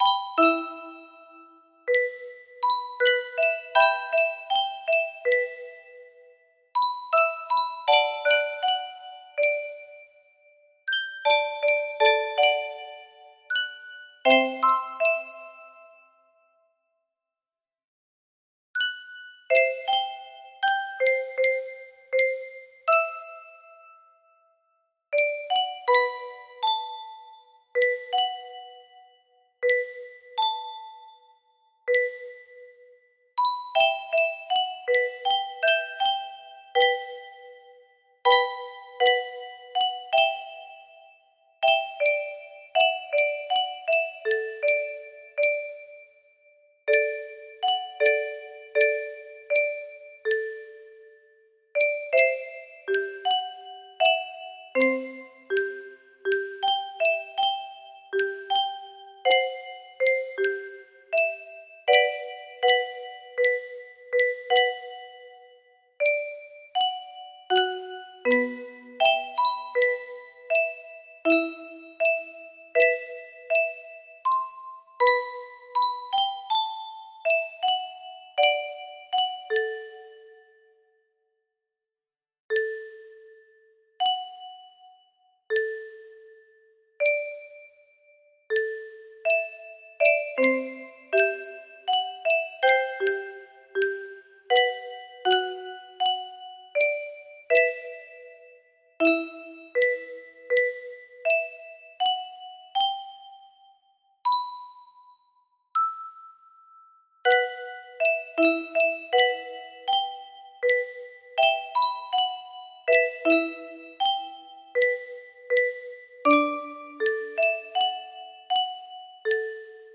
Whispers of All Time. music box melody